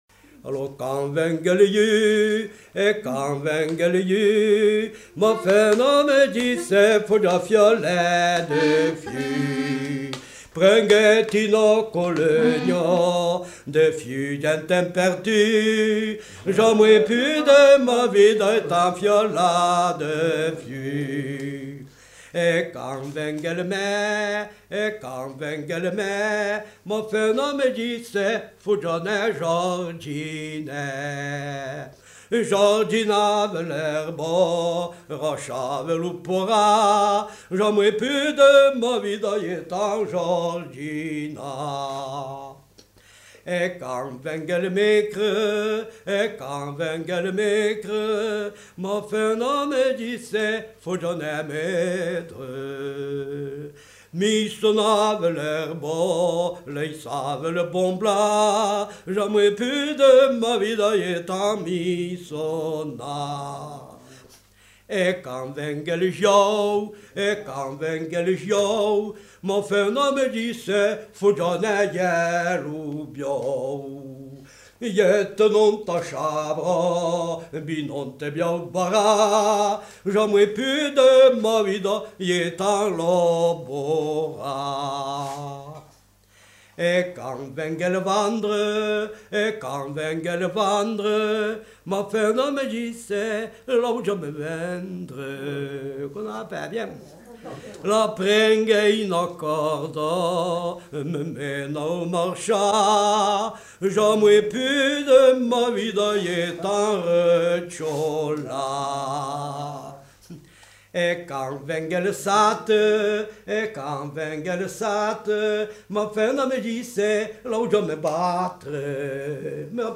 Lieu : Peschadoires
Genre : chant
Effectif : 1
Type de voix : voix d'homme
Production du son : chanté